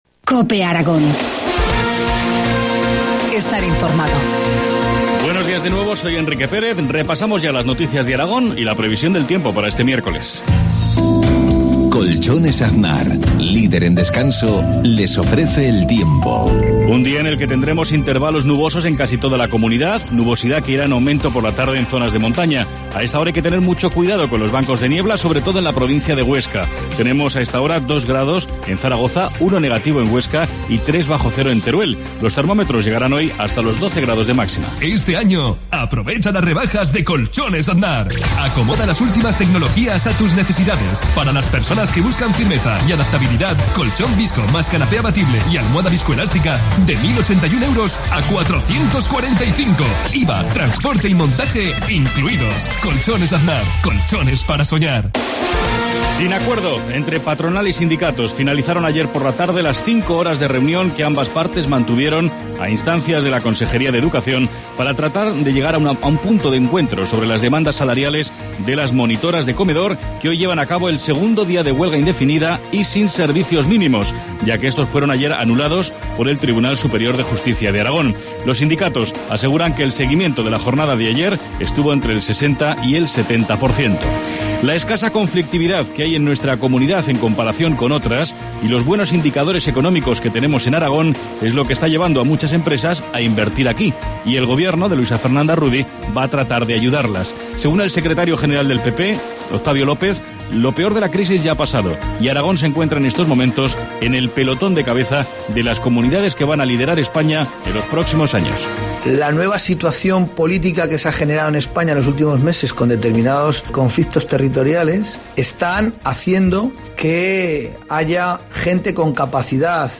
Informativo matinal, miércoles 9 de enero, 7.53 horas